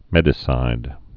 (mĕdĭ-sīd)